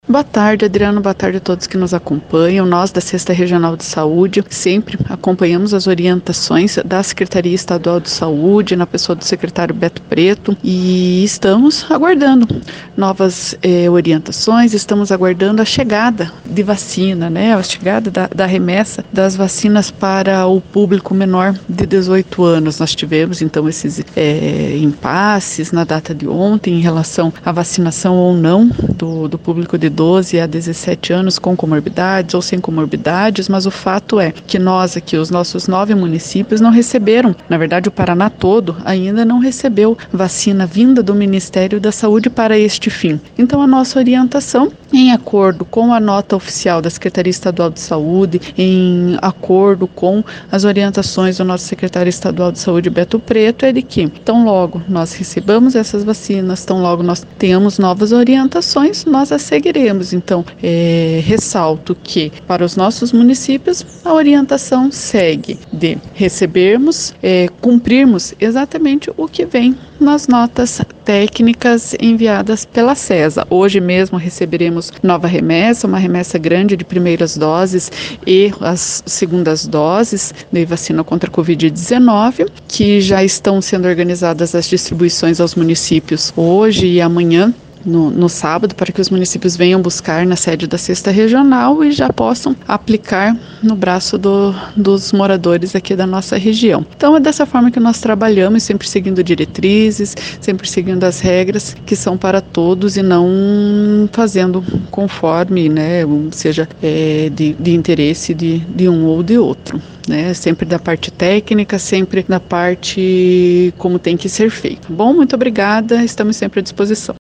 Ouça o áudio da Chefe da Regional Paula Krzyzanowski: